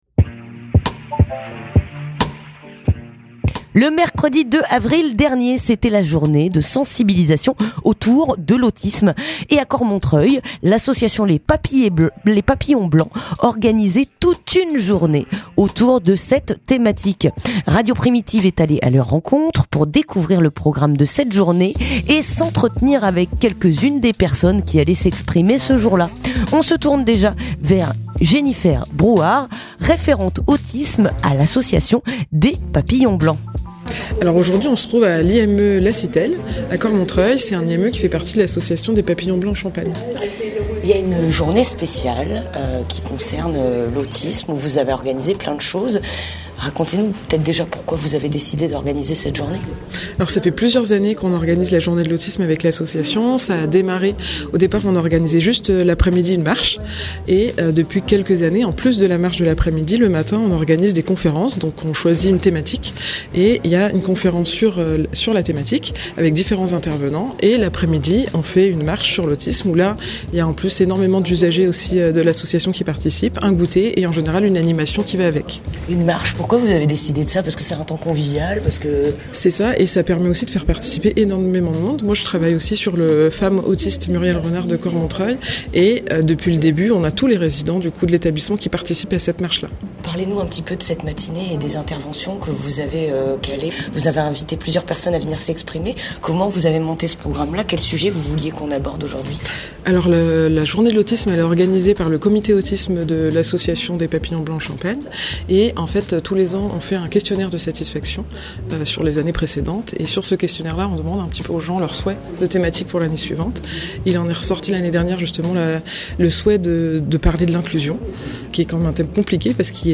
Reportage aux Papillons Blancs (15:03)